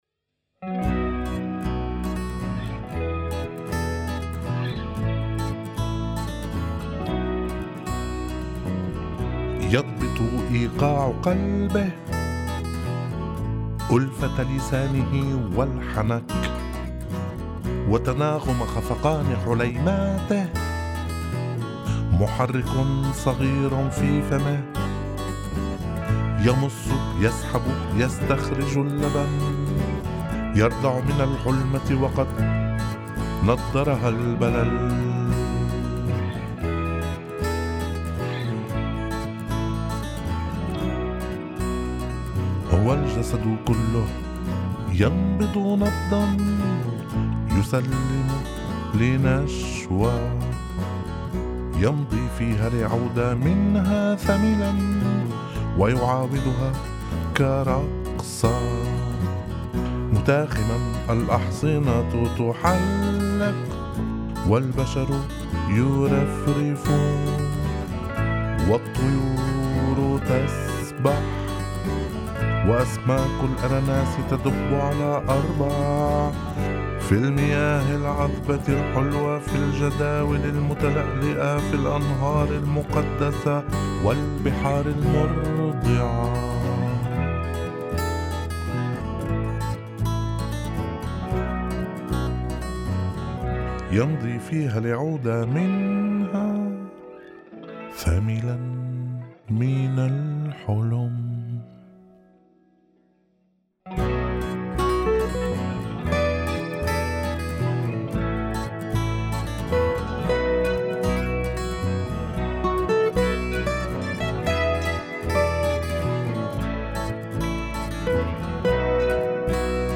chanson en arabe